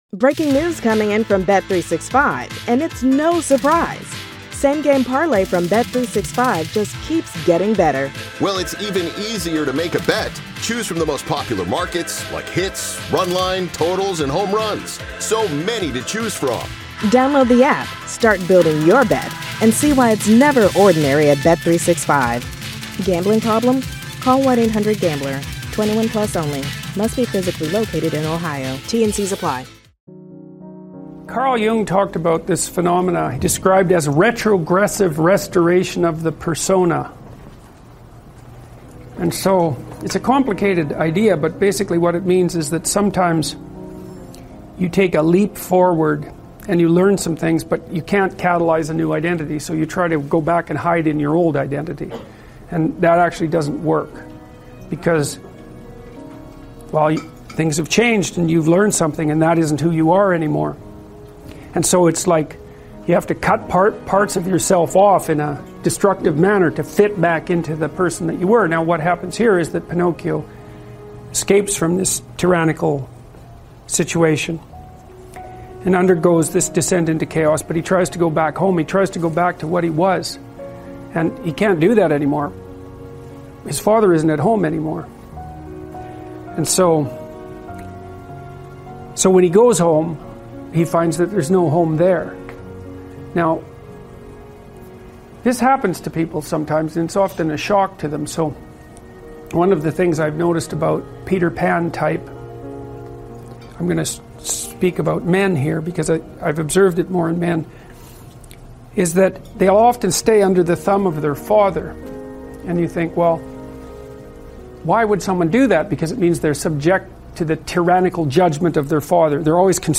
Speaker: Jordan Peterson